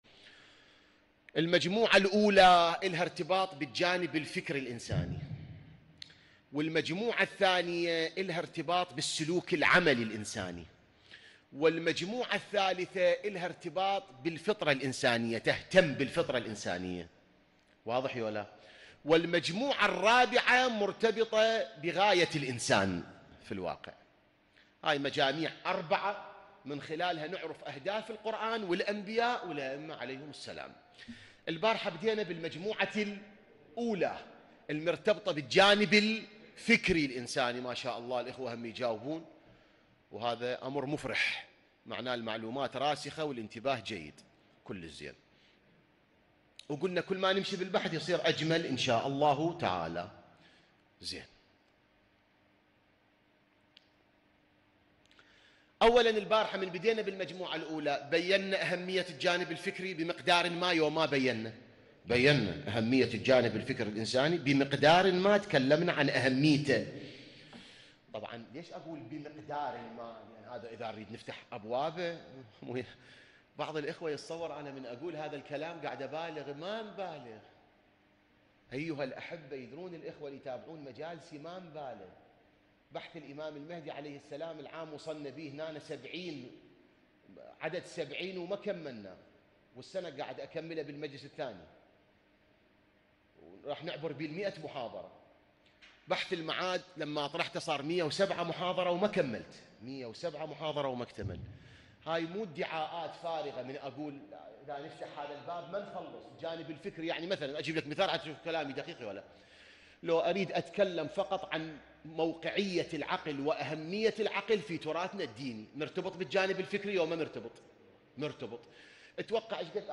ليلة 3 شهر رمضان 1443 هـ جامع الهدى البصرة